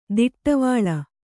♪ diṭṭavāḷa